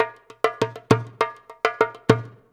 100DJEMB24.wav